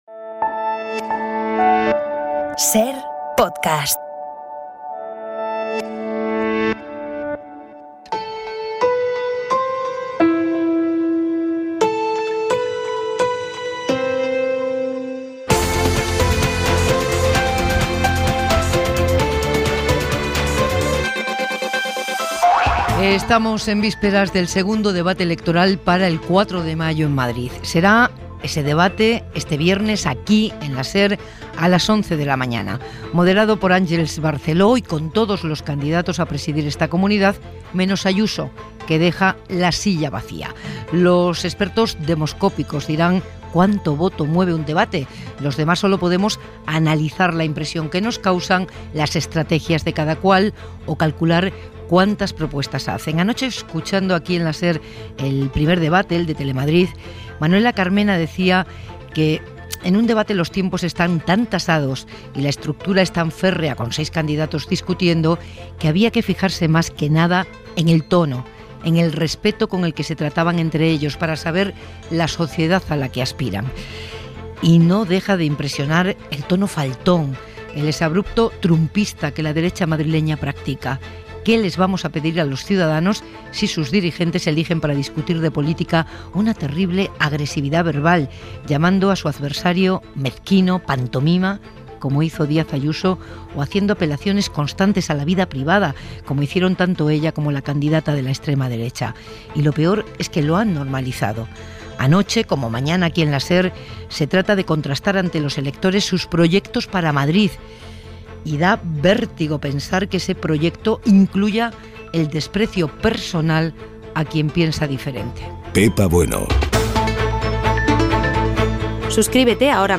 Careta d'entrada. Secció "La firma", d'"Hora 25", amb l'opinió de Pepa Bueno sobre el debat electoral que van tenir els candidats a presidir la Comunidad de Madrid a Tele Madrid.
Informatiu